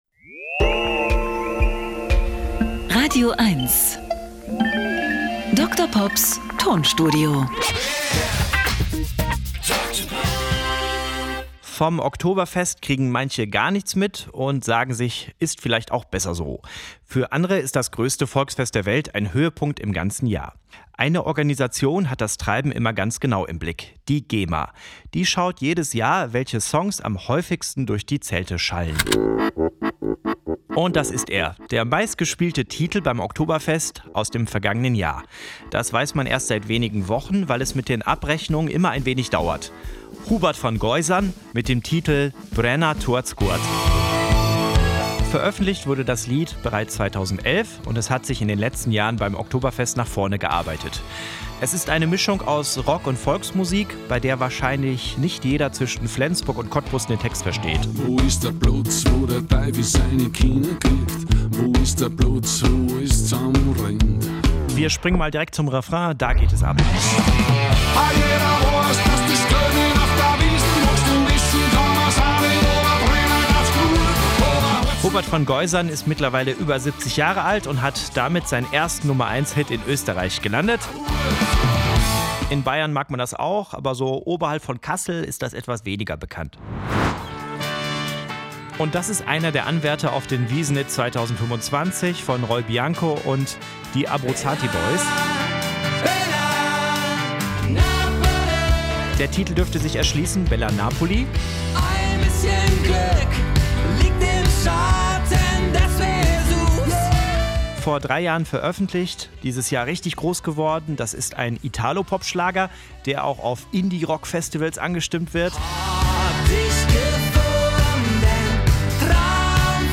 Er therapiert mit Musiksamples und kuriosen, aber völlig wahren Musikfakten.
Comedy